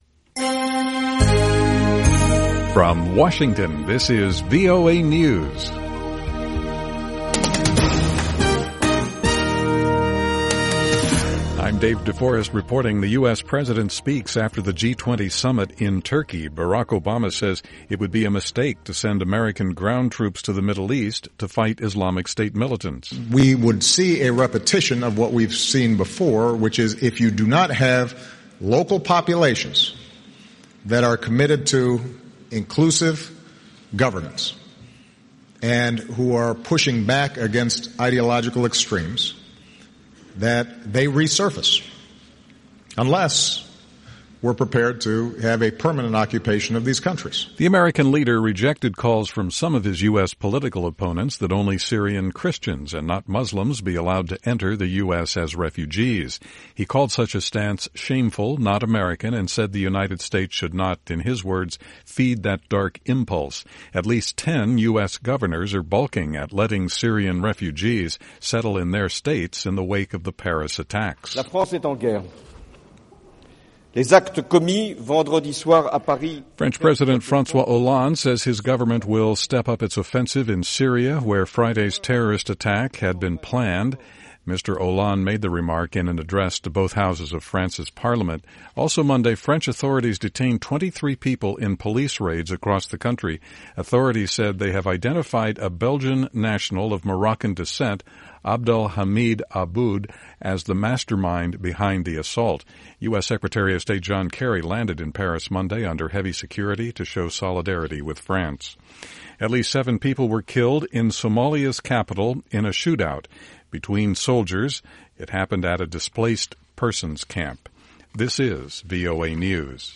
VOA English Newscast 2200 November 16, 2015